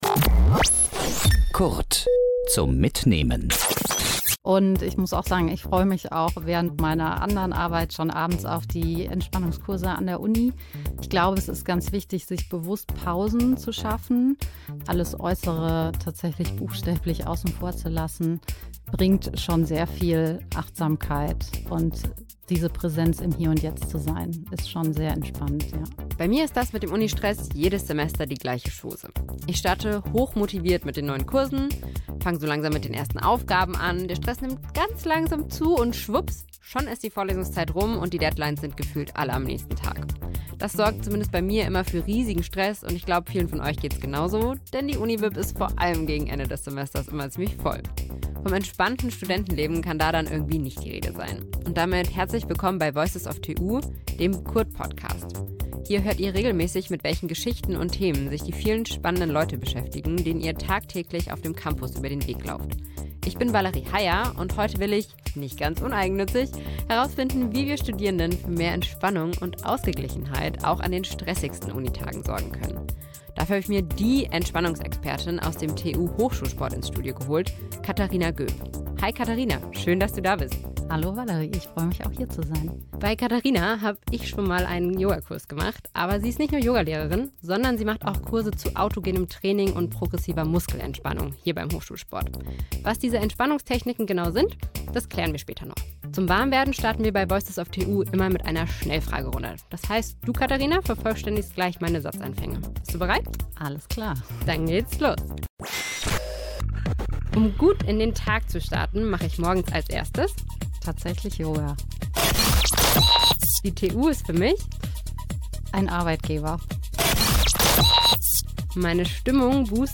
verrät sie im Interview. Sie hat auch eine kleine Mitmachübung für die Atmung im Gepäck.